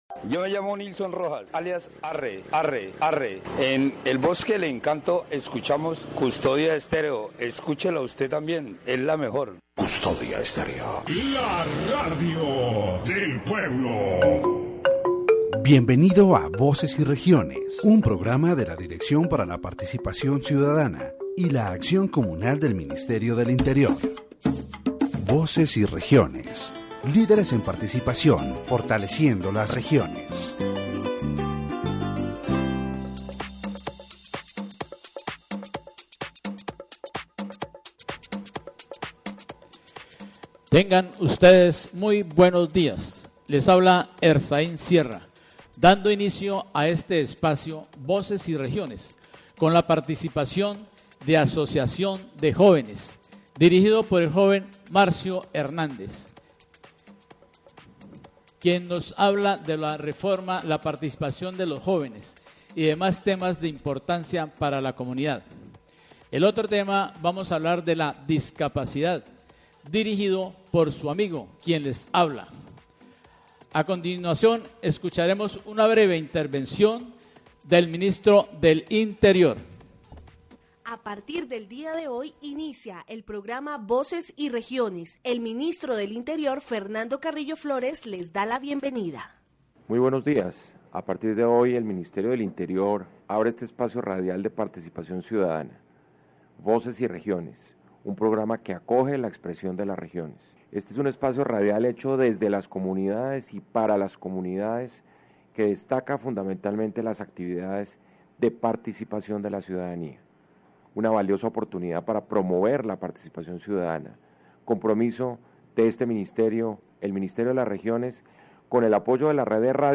In this section of the Voces y Regiones program, the Minister of the Interior, Fernando Carrillo Flórez, presents the radio station and welcomes listeners who wish to participate in the nation-building process. The discussion also focuses on the inclusion of people with disabilities within the framework of Law 1145 of July 10, 2007, in Colombia.